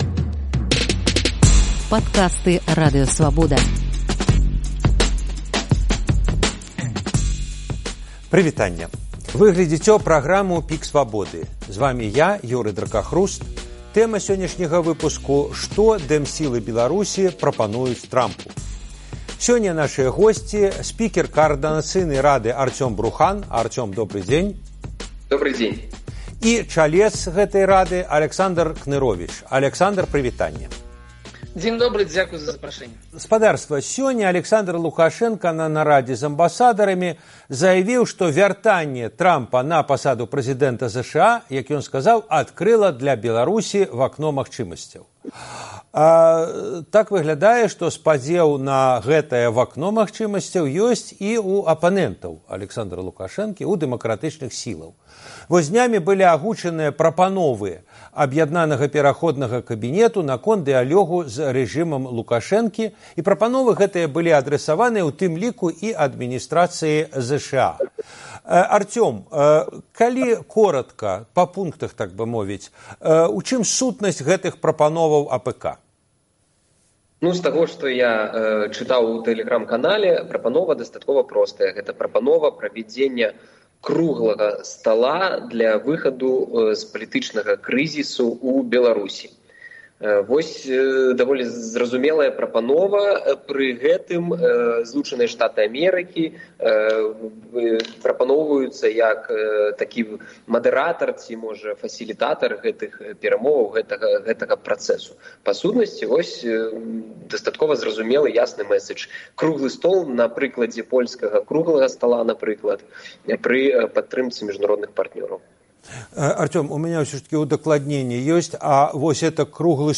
Як мяняюць сытуацыю ў Беларусі санкцыі, якія рыхтуе Трамп адносна Расеі? Дыскусія